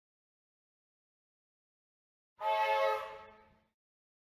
Mudanças entre as edições de "Arquivo:Buzinadetrem-audio.ogg"